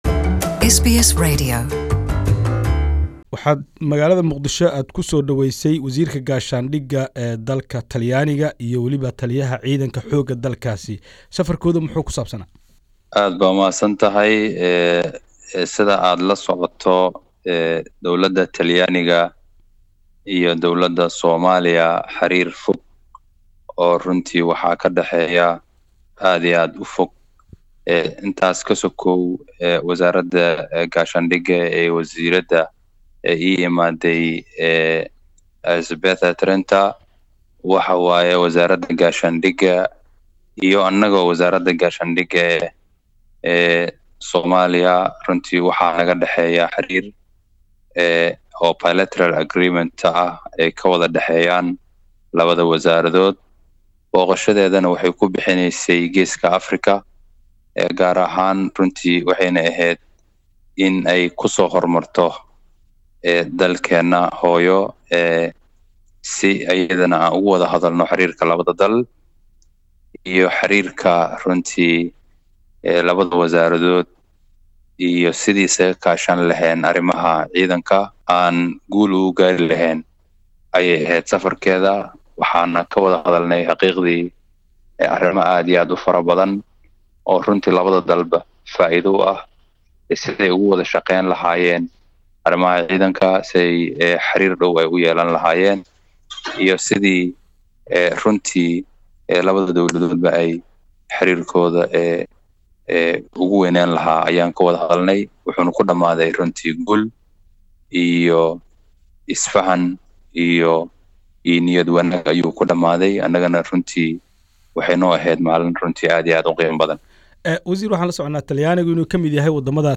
Interview: Somali defence minister, Hassan Ali